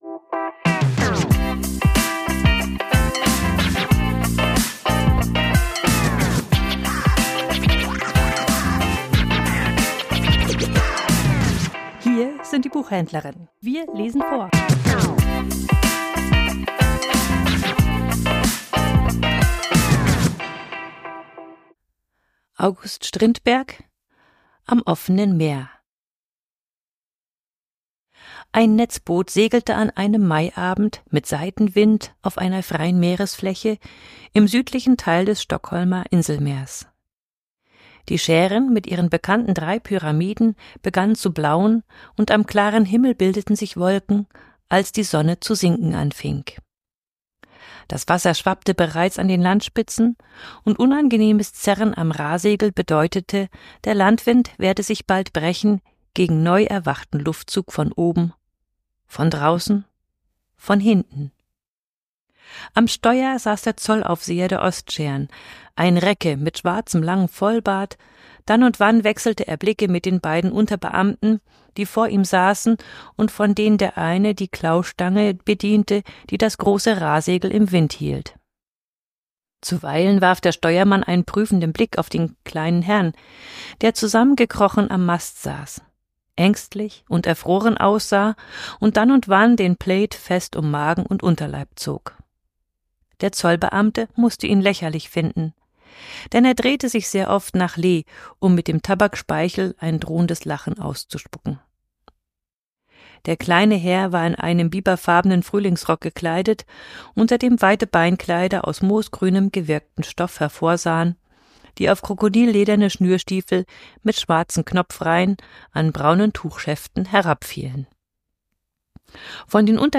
Vorgelesen: Am offenen Meer